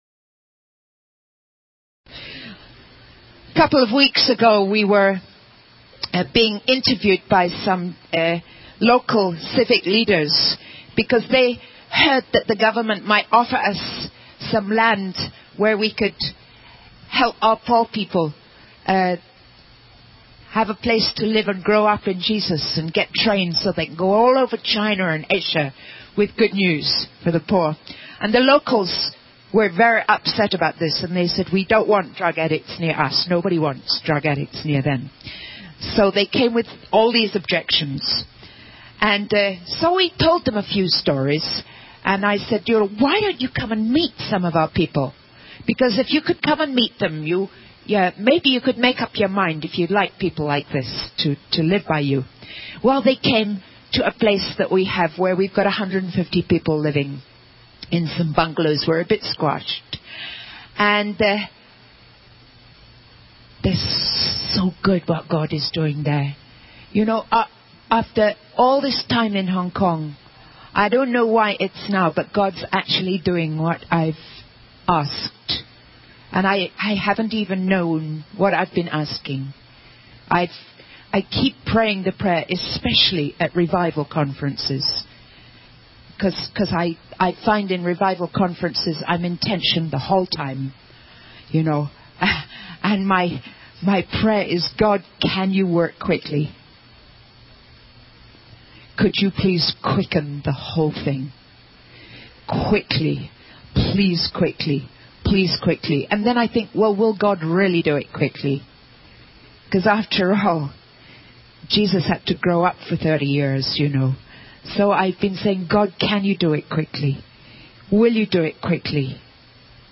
In this sermon, the speaker discusses the concept of contentment in the face of different circumstances. He references the Apostle Paul's experience in Philippians, where Paul states that he has learned to be content in any situation, whether in need or in plenty.